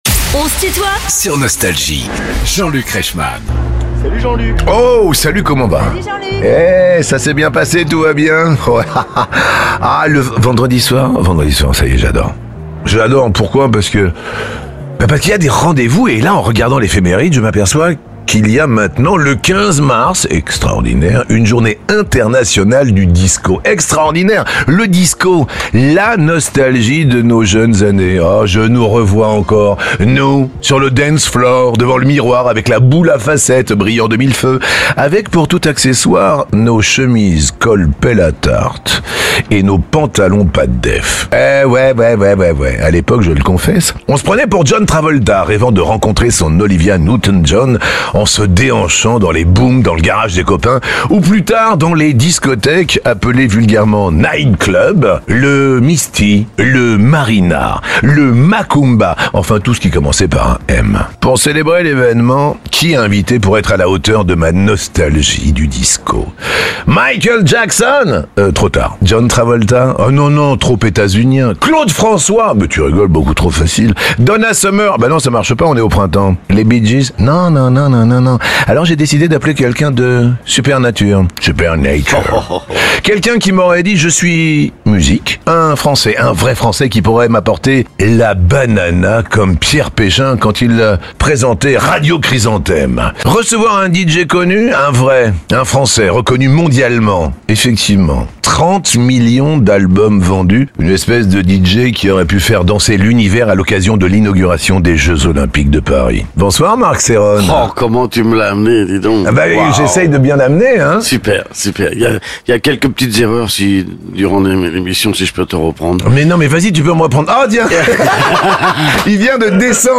Cerrone : mon tube "Supernature" a enflammé les Jeux de Paris 2024 et m'a donné une reconnaissance en France (Partie 1) ~ Les interviews Podcast